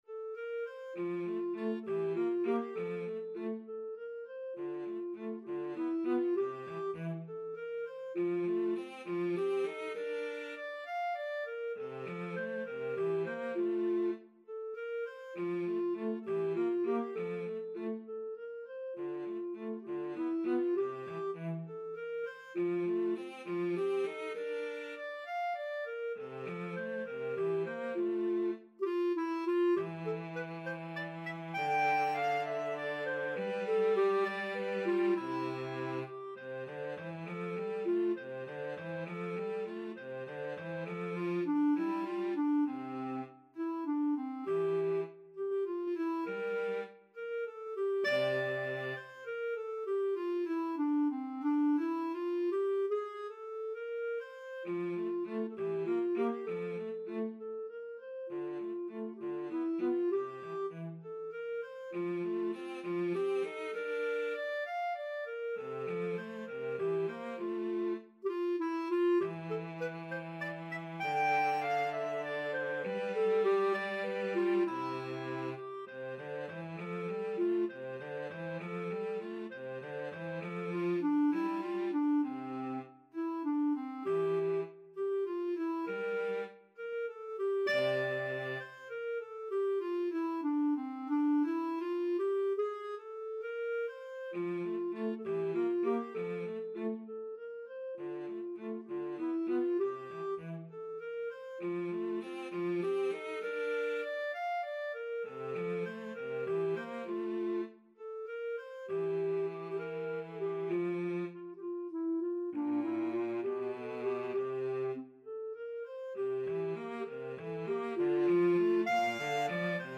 Free Sheet music for Clarinet-Cello Duet
F major (Sounding Pitch) G major (Clarinet in Bb) (View more F major Music for Clarinet-Cello Duet )
6/8 (View more 6/8 Music)
Allegretto
Classical (View more Classical Clarinet-Cello Duet Music)